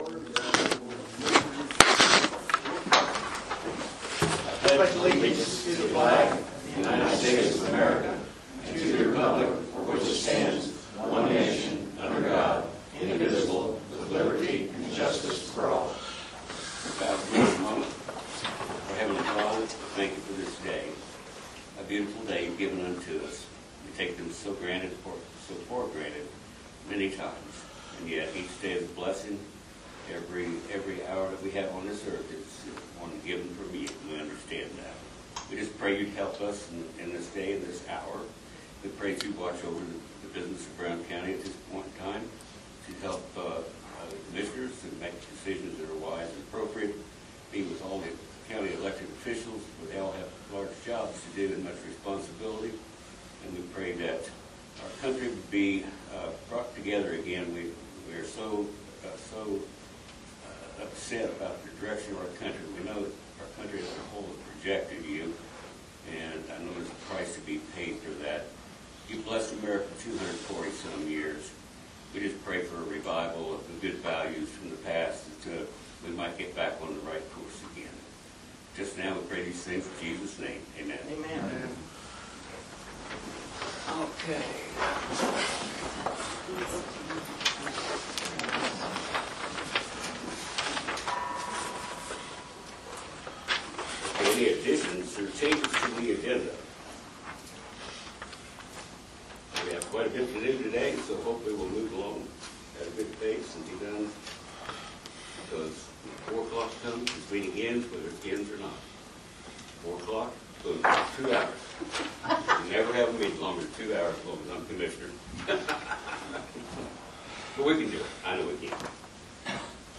Audio of the meeting and ….